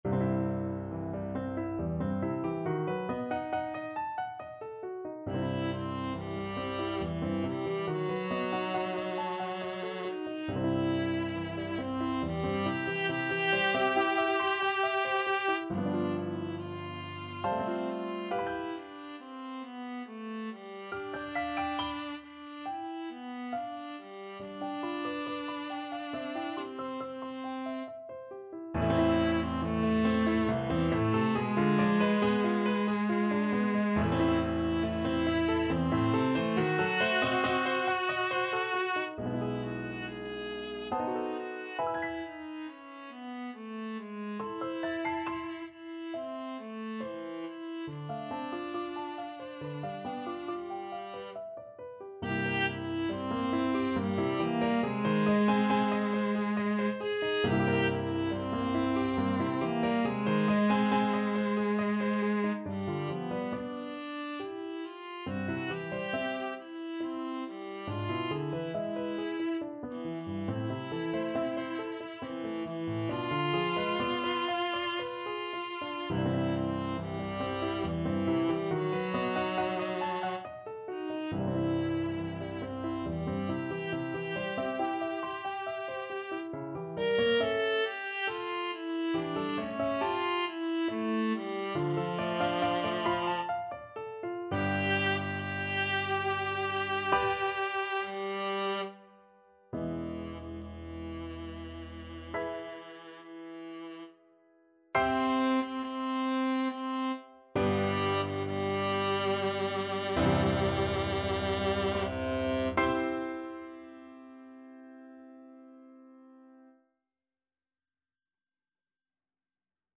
3/4 (View more 3/4 Music)
C major (Sounding Pitch) (View more C major Music for Viola )
~ = 69 Large, soutenu
Viola  (View more Intermediate Viola Music)
Classical (View more Classical Viola Music)